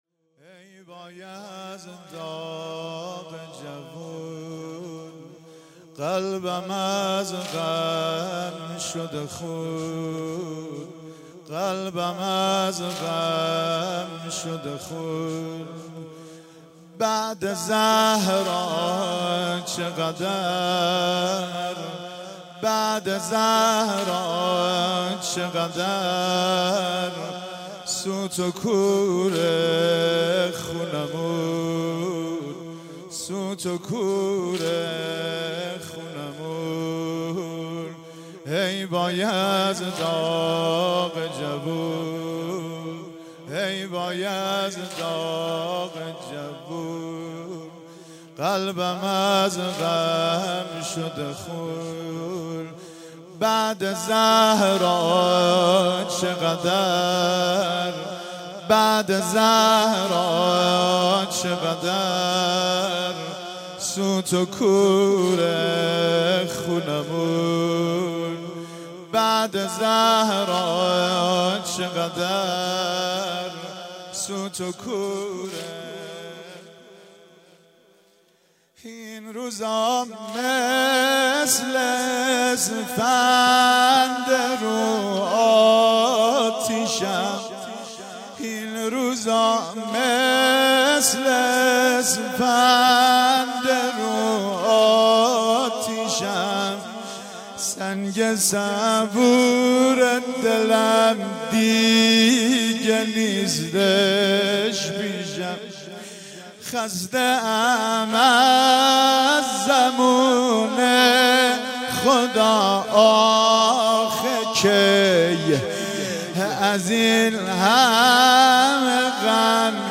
فاطمیه 96 - واحد - ای وای از داغ جوون